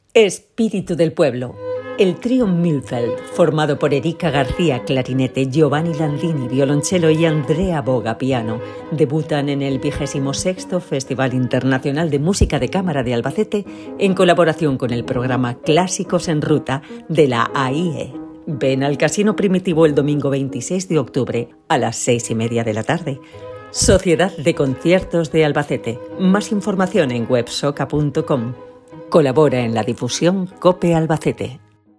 CUÑA radiofónica COPE Albacete